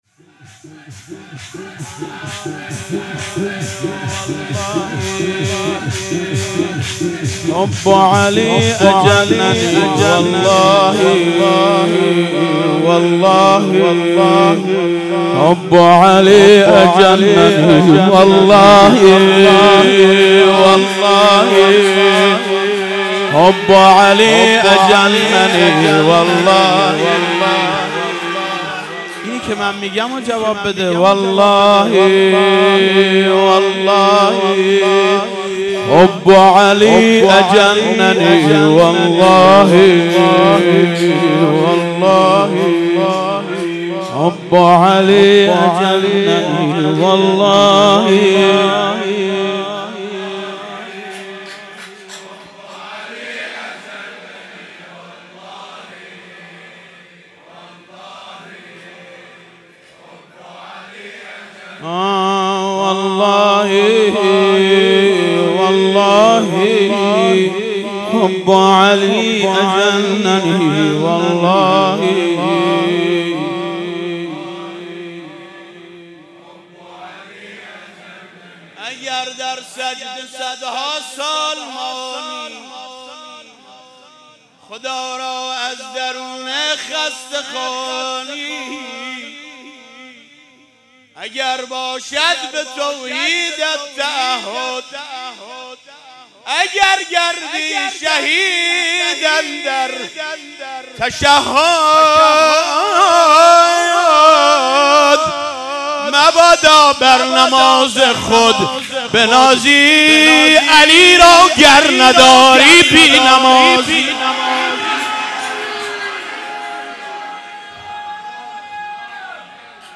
مراسم هفتگی/29آذر97